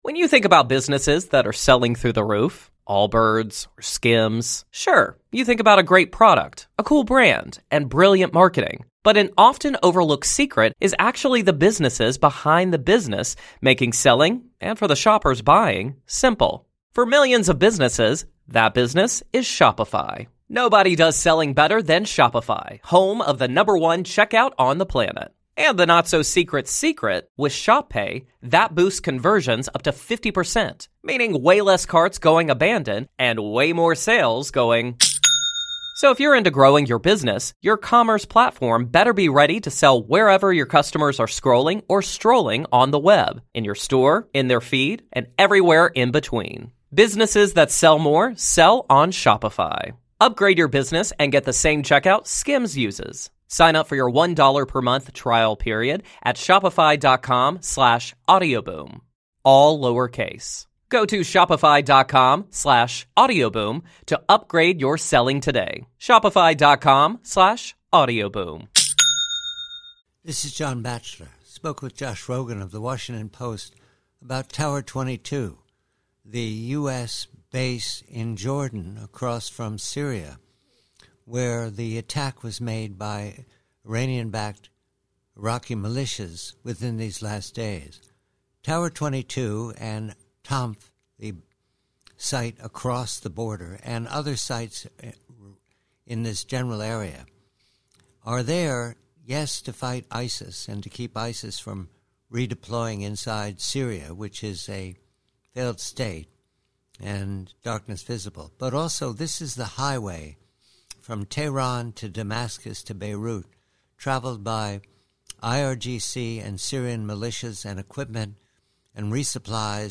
PREVIEW: From a conversation with Josh Rogin of the Washington Post re the strategic mission for Tower 22 snd the network of US outposts in the tri-border region of Jordan, Syria and Iraq -- and why Tahran wants the US to leave the region.